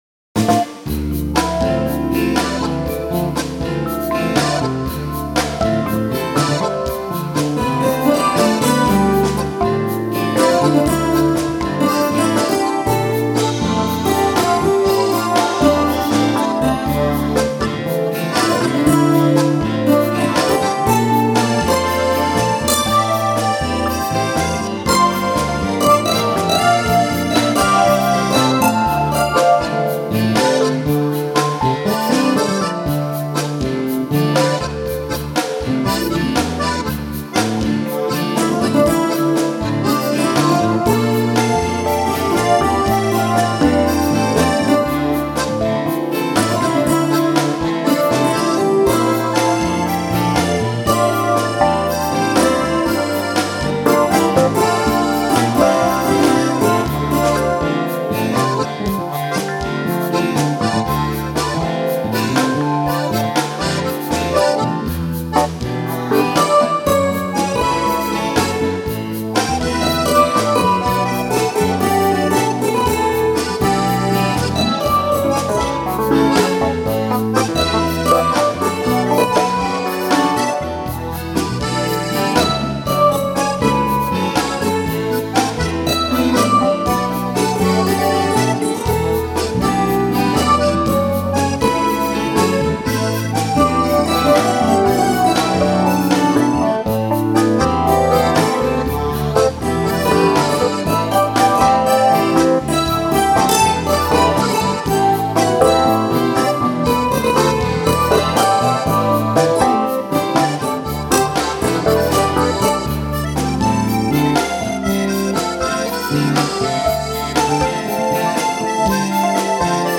Keyboards, Accordion, Guitar, Ukulele, Bass Guitar, Drums
Trumpet, Flumpet and all Brass Instruments
Made in Hawai'i